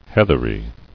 [heath·er·y]